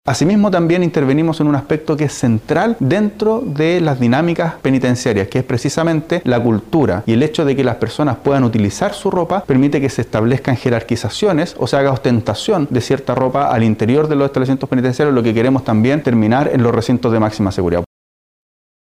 Pero también, el secretario de Estado añadió que, con un plan de estas características, se interviene en un aspecto central de las dinámicas penitenciarias: la cultura de la jerarquización, donde el vestuario de los reclusos forma parte fundamental.